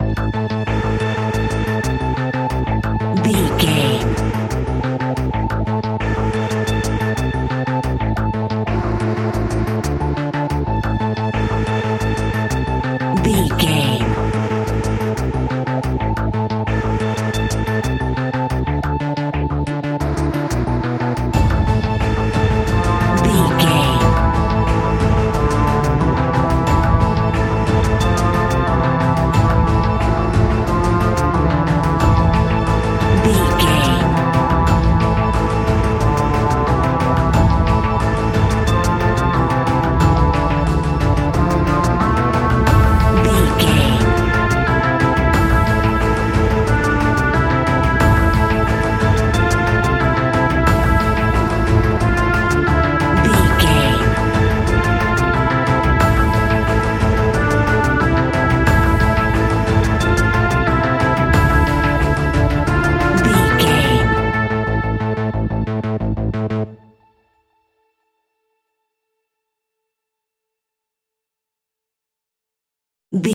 Aeolian/Minor
WHAT’S THE TEMPO OF THE CLIP?
ominous
dark
haunting
eerie
synthesiser
drums
percussion
instrumentals
horror music